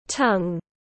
Lưỡi tiếng anh gọi là tongue, phiên âm tiếng anh đọc là /tʌŋ/.
Tongue /tʌŋ/